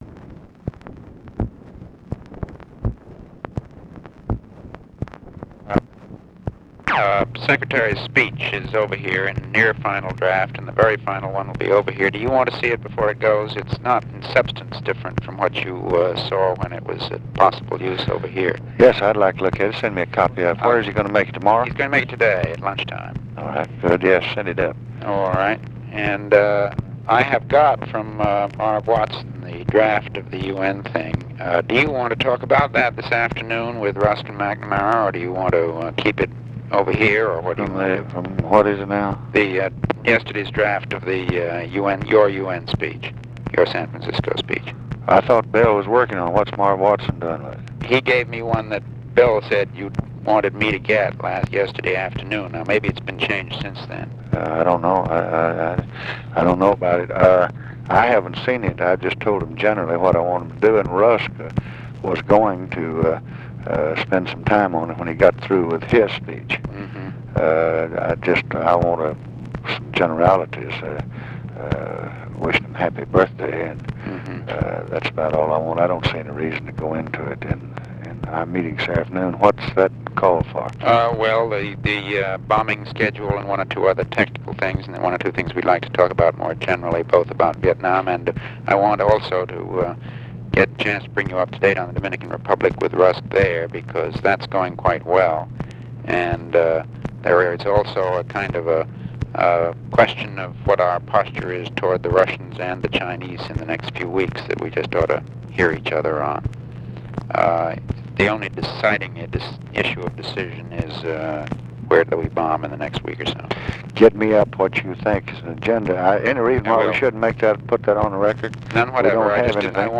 Conversation with MCGEORGE BUNDY, June 23, 1965
Secret White House Tapes